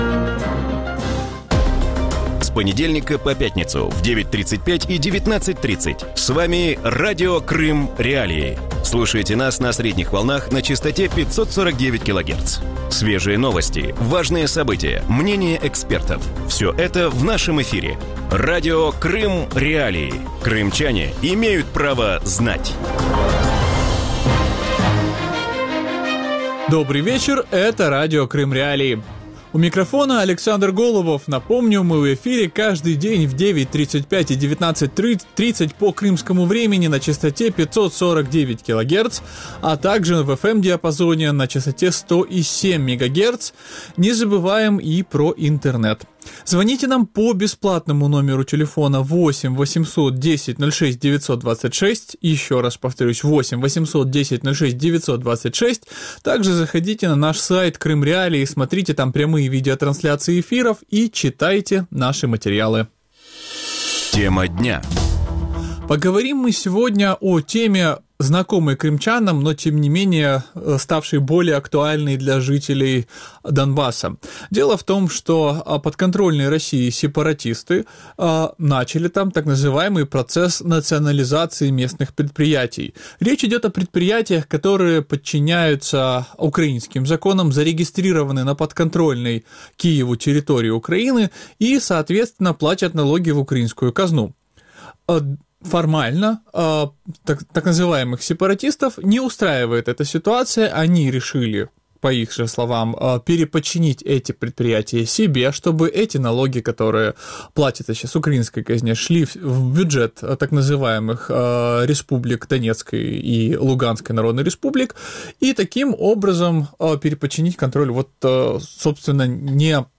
У вечірньому ефірі Радіо Крим.Реалії говорять про хвилю експропріації бойовиками підприємств на непідконтрольній Україні території. Що означає так звана «націоналізація» українського майна, яким Кремль бачить майбутнє тимчасово окупованих територій на сході України і чи чекає непідконтрольні Україні регіони Донбасу доля Криму?